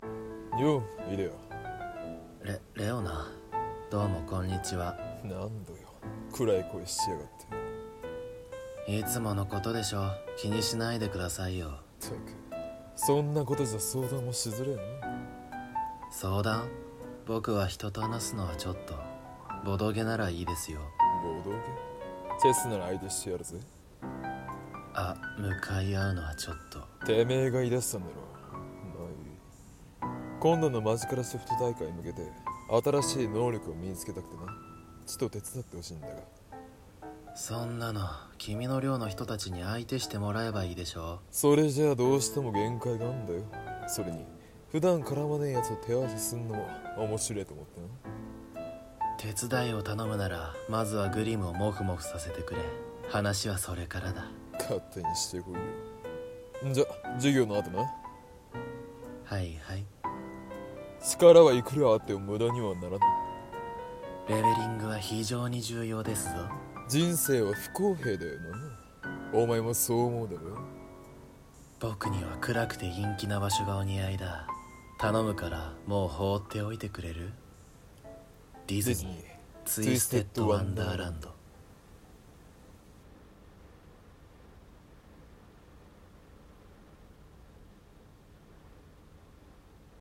ツイステ声劇！